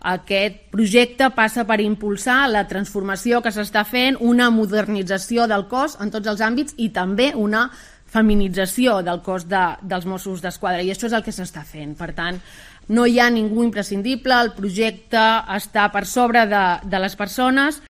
La portavoz del Govern de la Generalitat de Catalunya, Patrícia Plaja